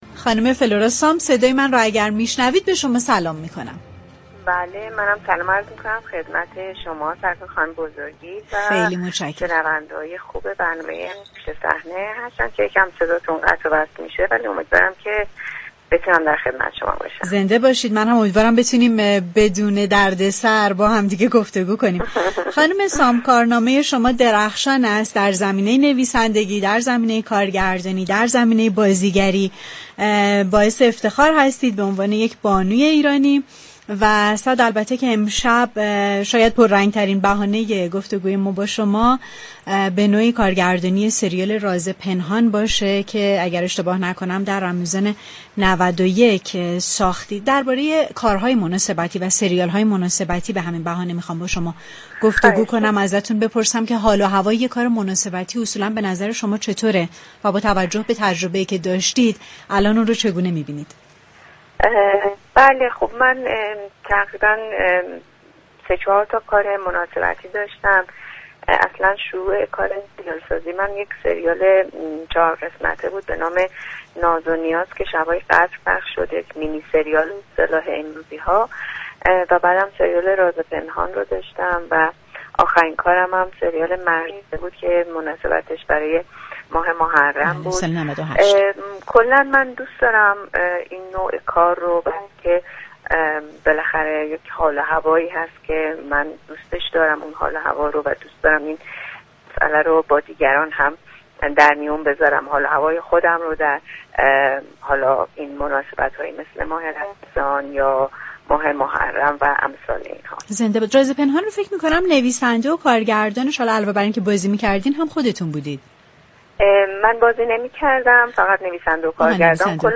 در گفتگوی تلفنی